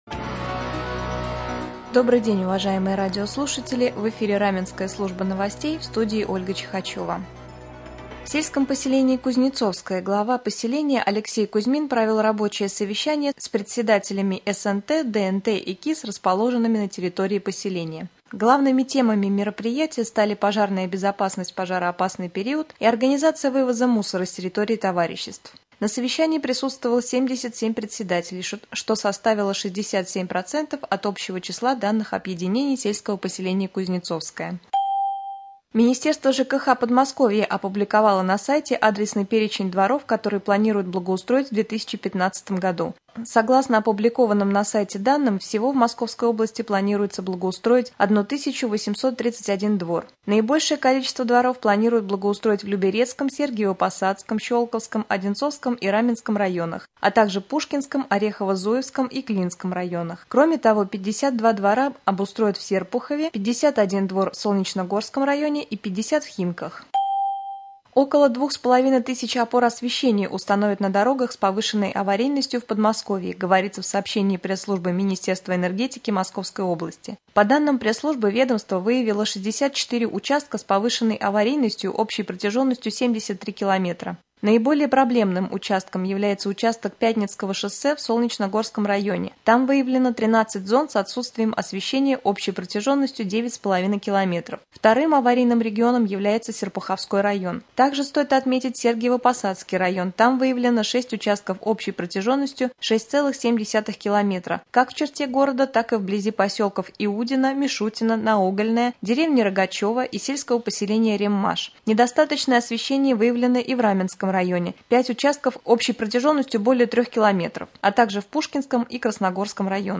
20.05.2015 г. в эфире Раменского радио - РамМедиа - Раменский муниципальный округ - Раменское